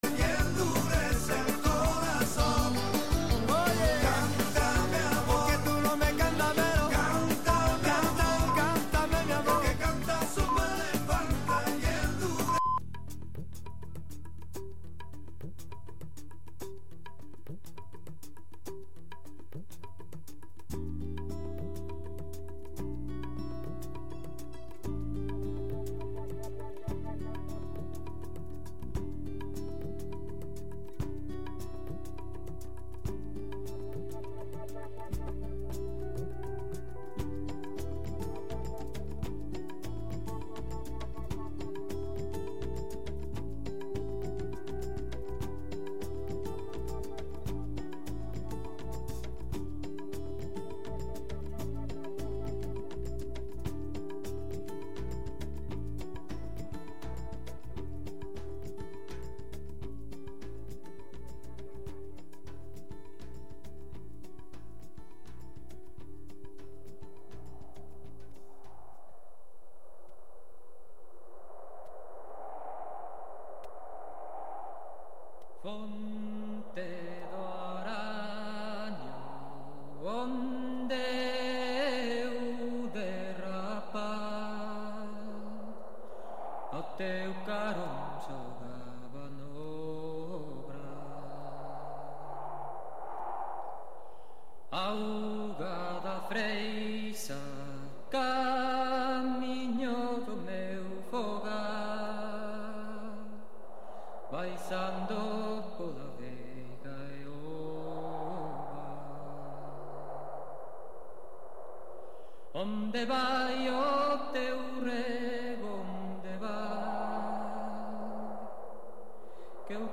Recorreguts musicals pels racons del planeta, música amb arrels i de fusió.